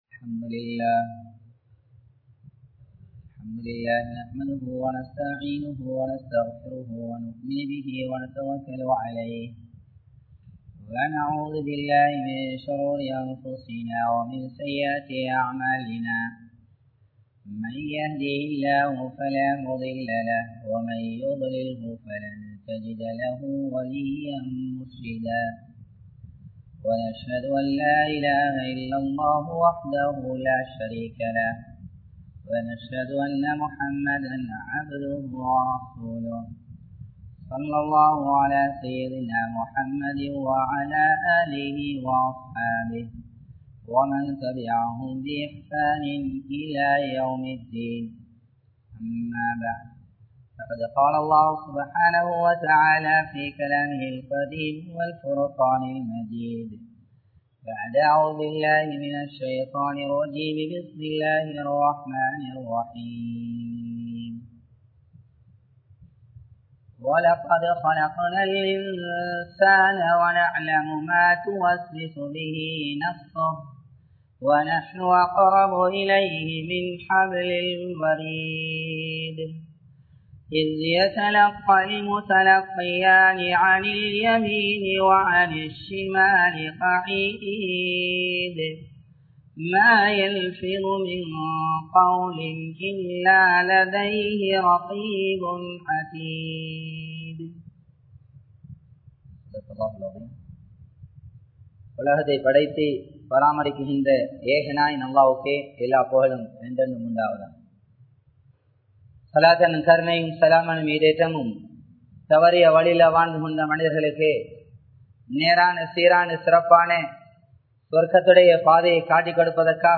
Naavin Vilaivuhal | Audio Bayans | All Ceylon Muslim Youth Community | Addalaichenai
Colombo 12, Aluthkade, Muhiyadeen Jumua Masjidh